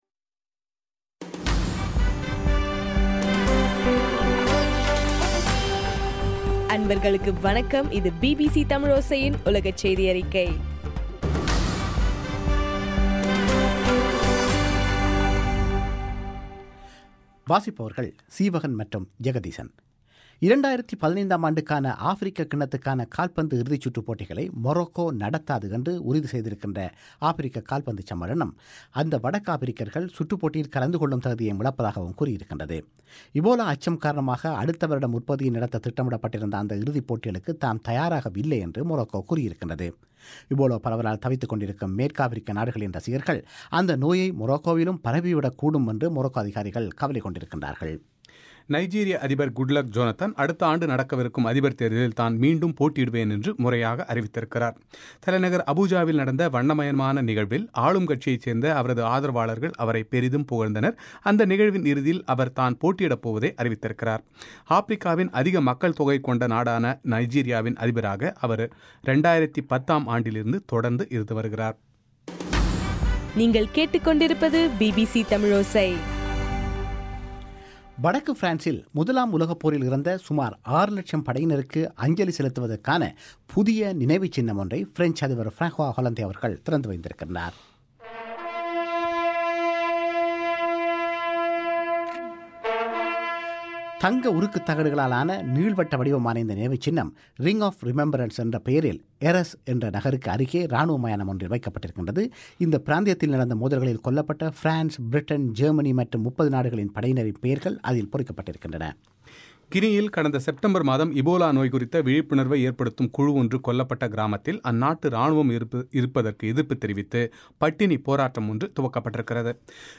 நவம்பர் 11 - பிபிசி உலகச் செய்திகள்